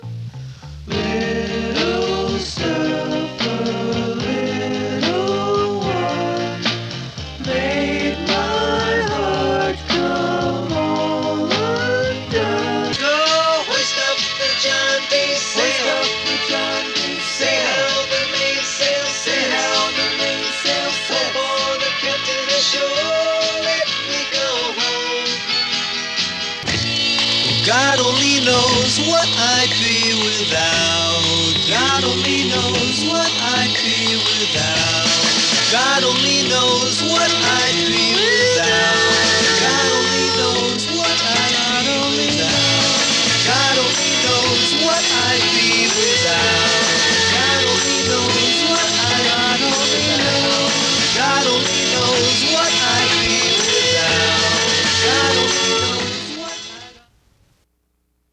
It’s audio only, and that’s me on all the vocals.